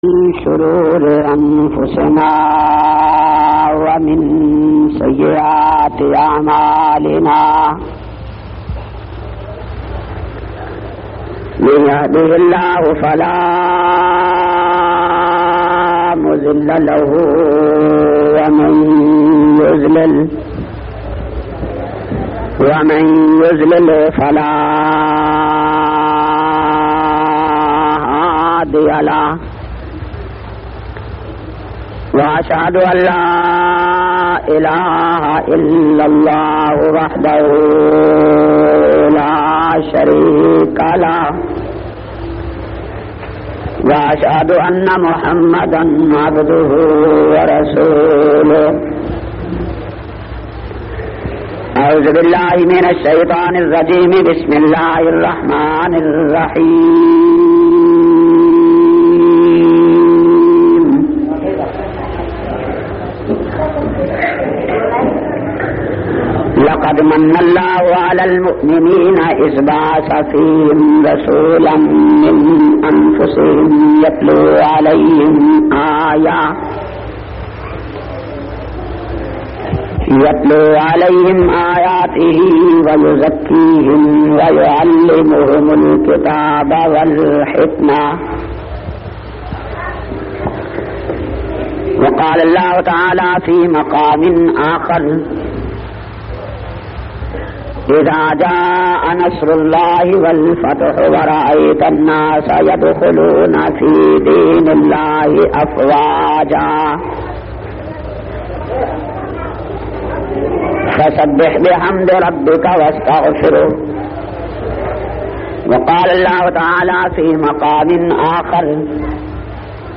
293- Sher e Khuda Conference Shan e Hazrat Ali Jhang.mp3